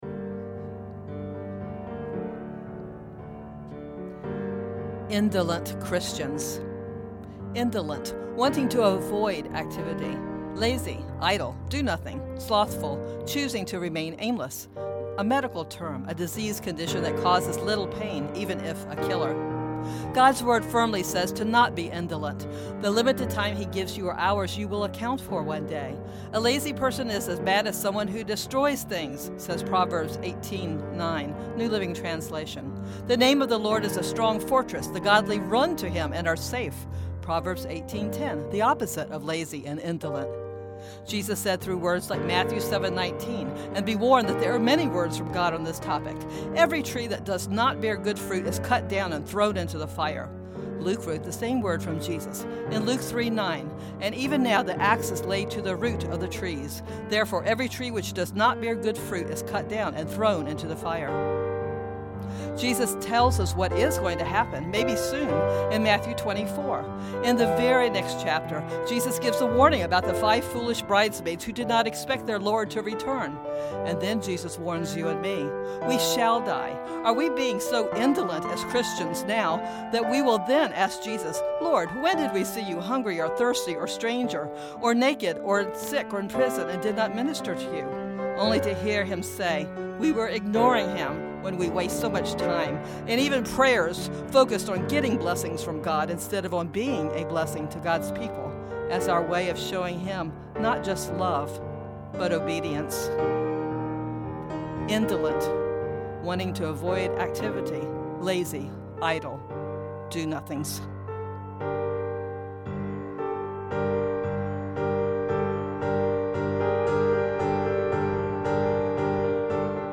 And by the time I went down to my home studio, I had the music in my mind, which I filled in with more colorful chords as the song progressed.